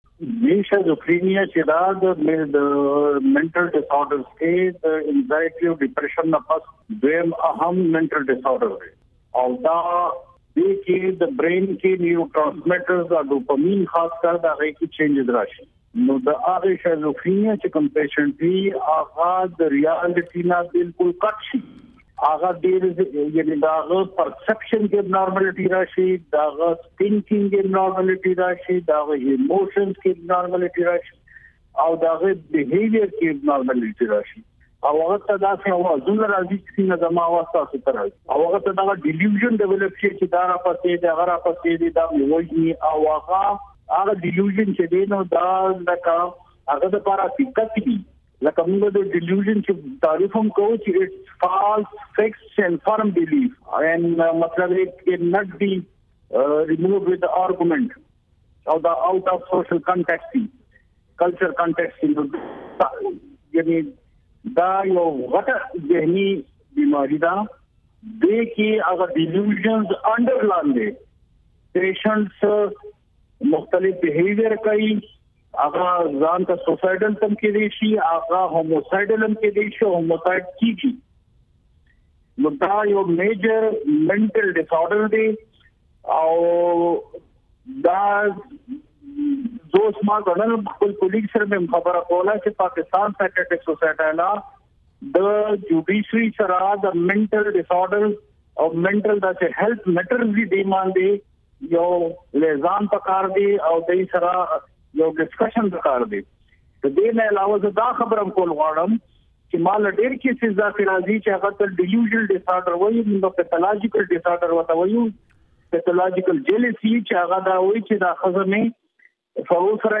په یوه ځانګړی مرکه کې